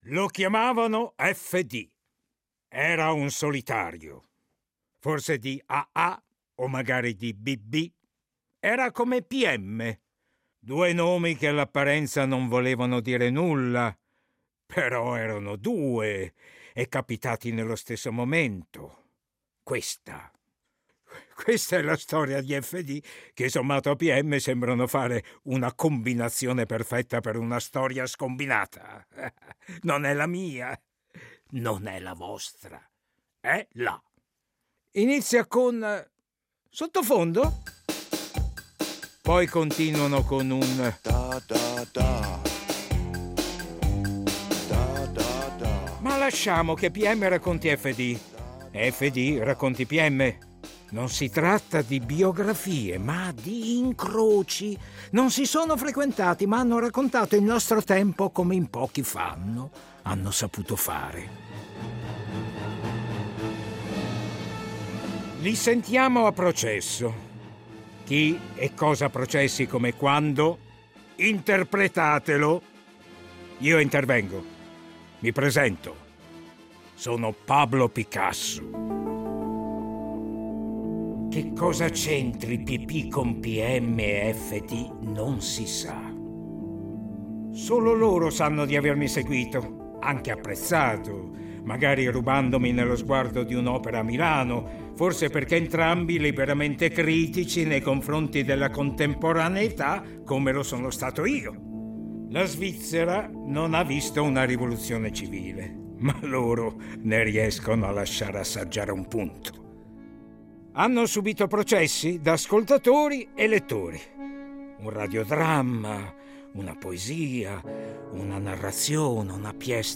Originale radiofonico